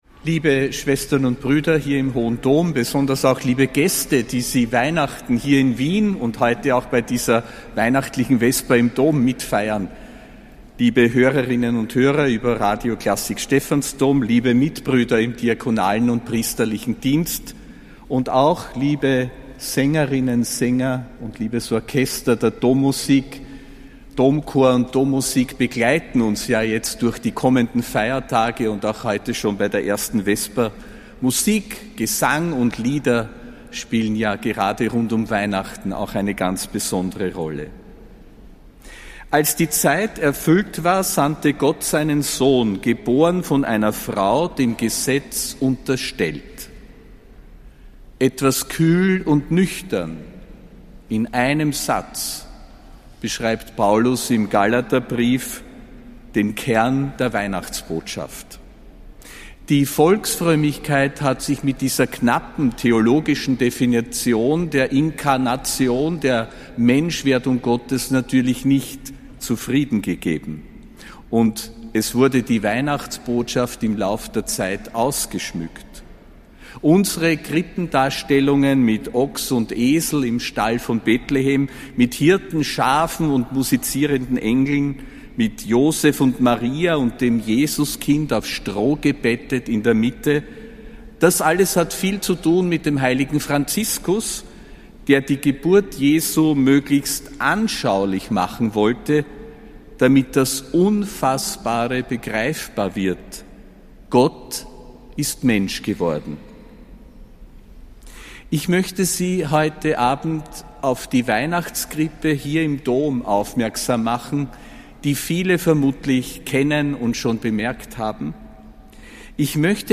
Ansprache von Josef Grünwidl zur 1. Weihnachtsvesper (24. Dezember 2025)